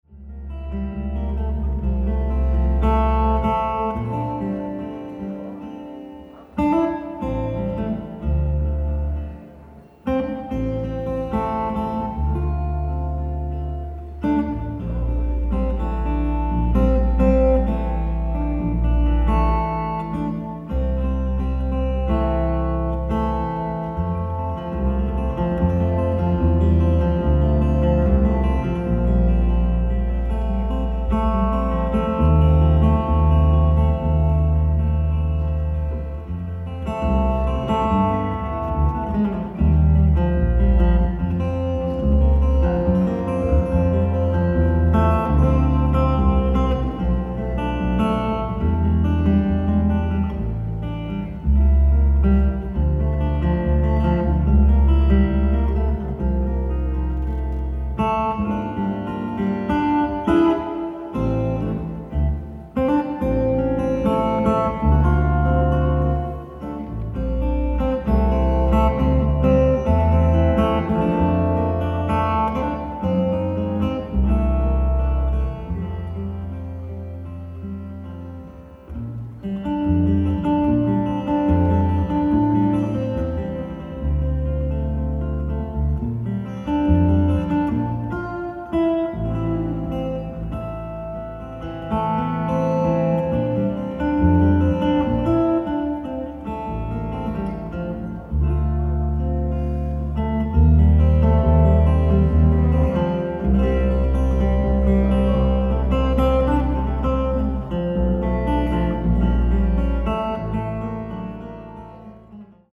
ライブ・アット・リマ市国立大劇場、リマ、ペルー 09/15/2025
※試聴用に実際より音質を落としています。